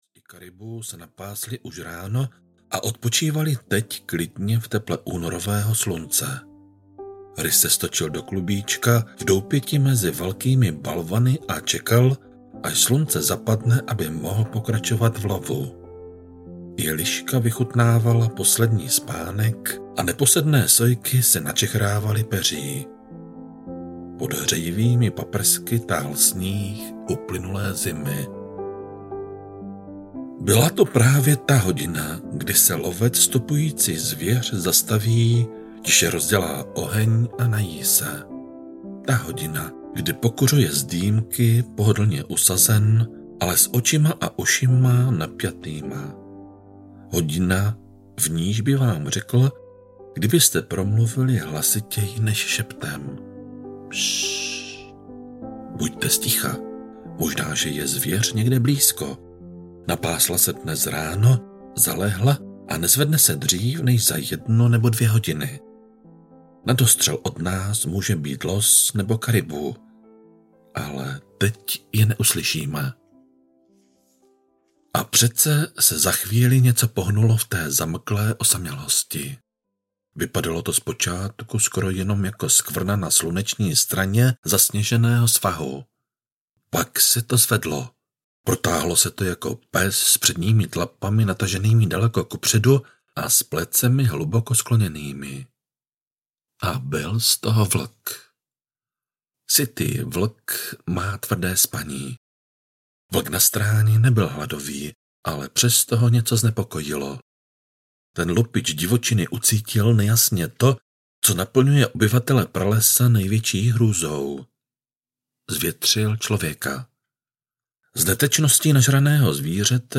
Zlaté údolí audiokniha
Ukázka z knihy
zlate-udoli-audiokniha